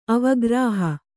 ♪ avagrāha